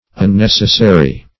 Unnecessary \Un*nec"es*sa*ry\, a.